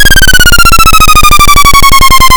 Title screen screech
This sound effect is produced after the theme tune has finished playing, and repeats until the 'Press ENTER to Start' message has finished scrolling across the screen (see 34993).
screech.ogg